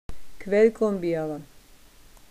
Wörterbuch der Webenheimer Mundart